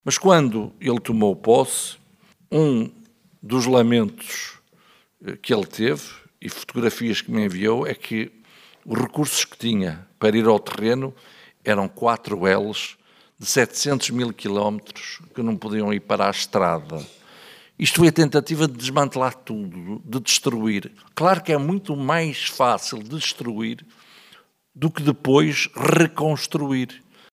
José Manuel Fernandes, esteve em Mirandela, na apresentação da candidatura da AD à Câmara Municipal.